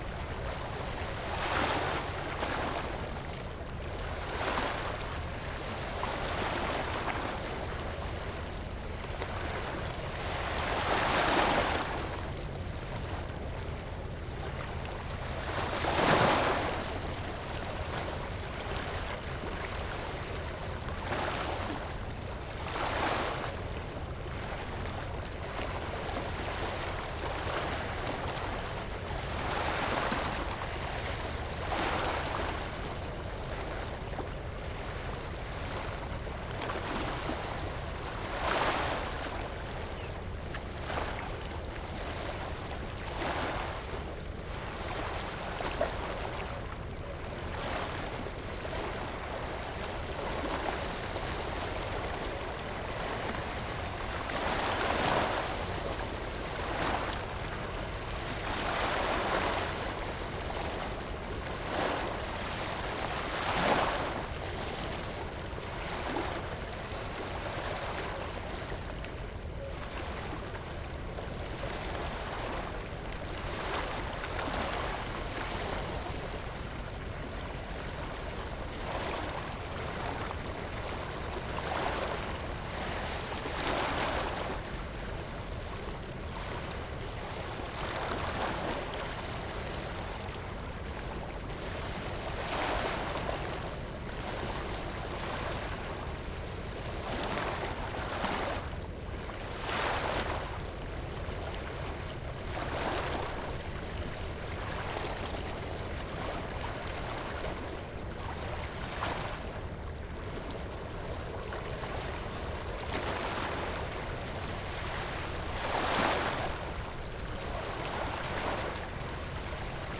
harbour waves calm 01
ambience ambient beach breaking breaking-waves calm field-recording general-noise sound effect free sound royalty free Memes